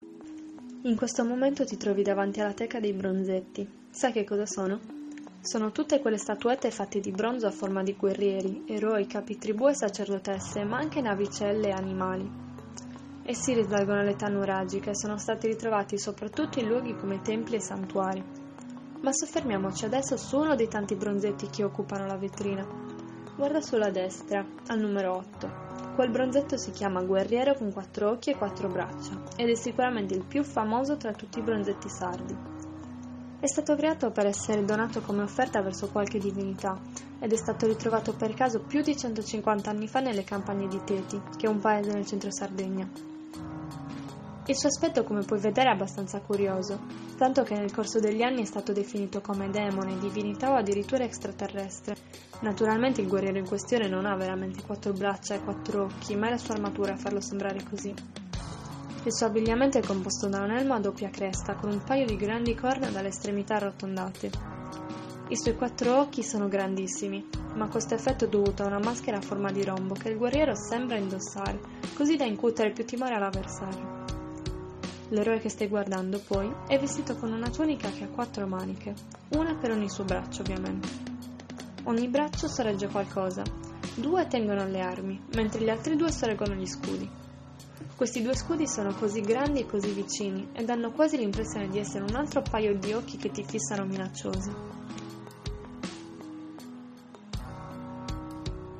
(Italiano) audioguida - bronzetto 4 occhi e 4 braccia